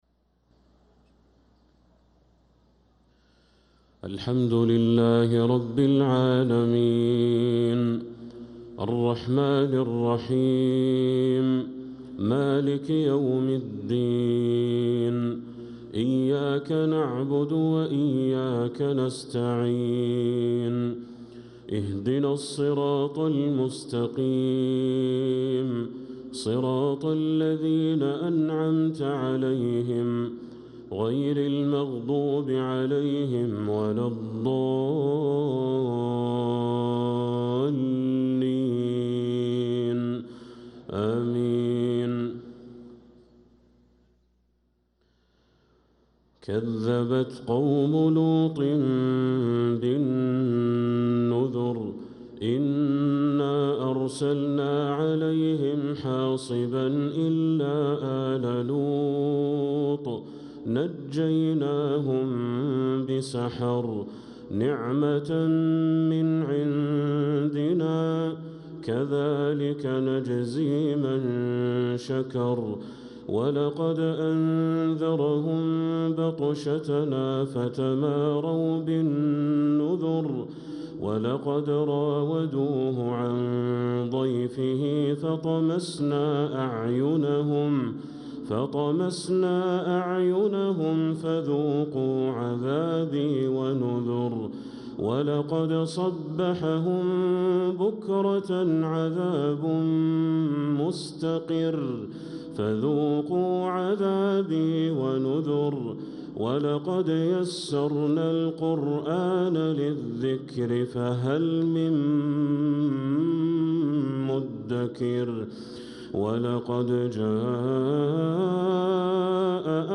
صلاة المغرب للقارئ بدر التركي 19 رجب 1446 هـ
تِلَاوَات الْحَرَمَيْن .